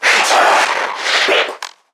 NPC_Creatures_Vocalisations_Infected [103].wav